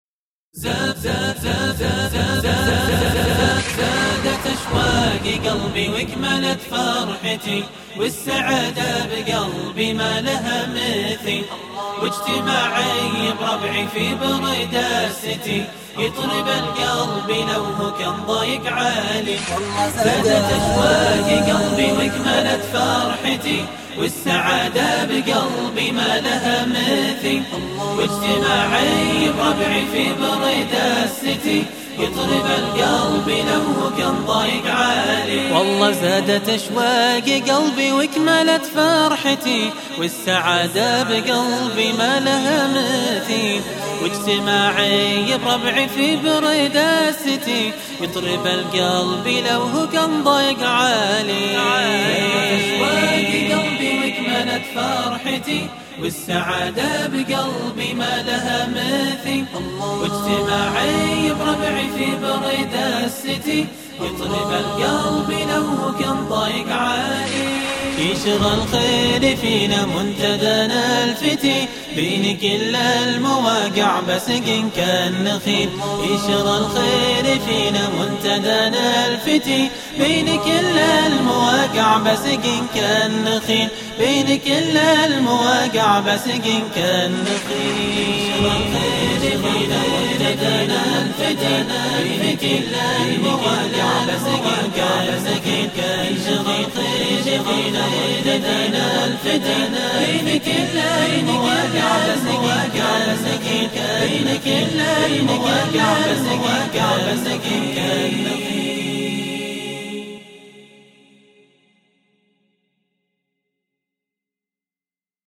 القصيدة الثانية : [ زادت أشواق قلبي ] تسجيل جديد غير الأولى ..
من كلمات الشاعر المنشد : حامد الضبعان